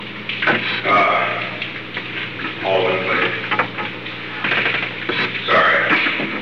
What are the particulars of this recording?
The Oval Office taping system captured this recording, which is known as Conversation 462-003 of the White House Tapes.